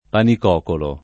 panicocolo [ panik 0 kolo ] → panicuocolo